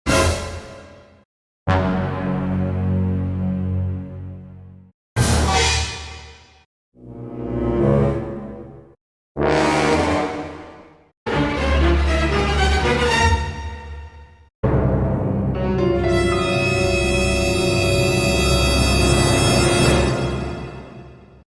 Suspense Gaming Sound Button | Sound Effect Pro
Instant meme sound effect perfect for videos, streams, and sharing with friends.